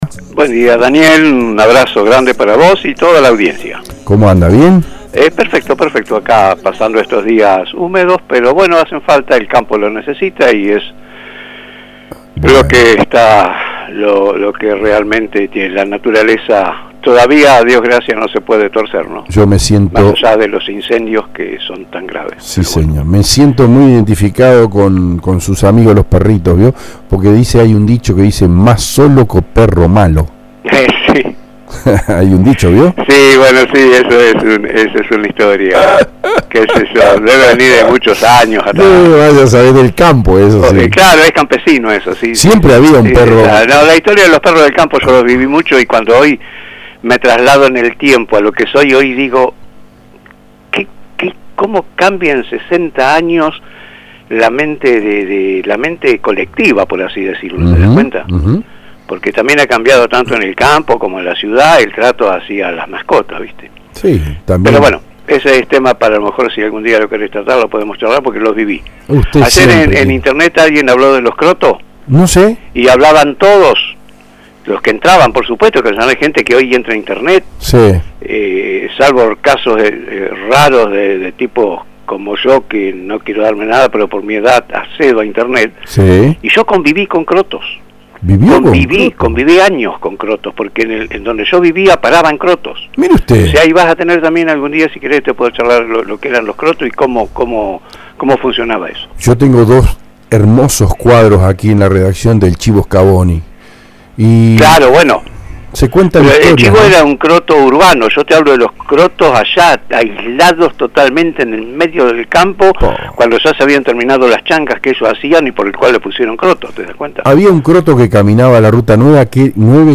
Acompañamos con los audios en vivo.